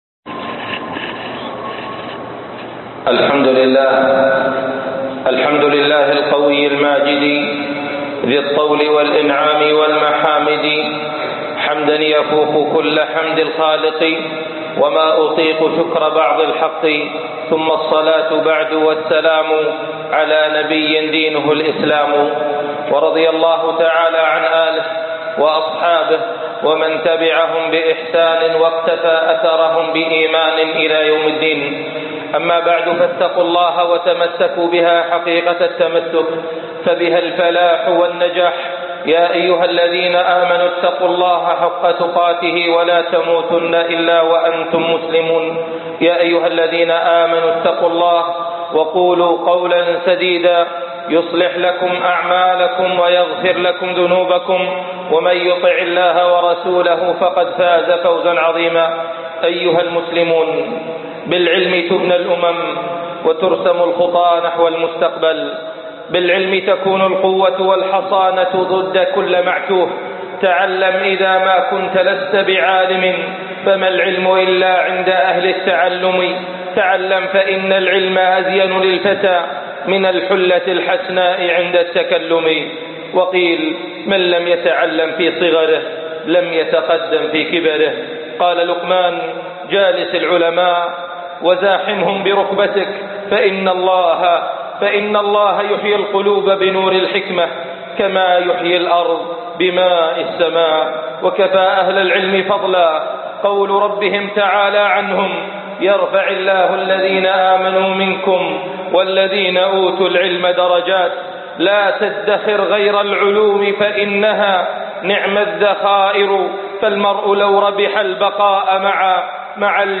هديّ النبي صلى الله عليه وسلم مع الطلب - خطب الجمعه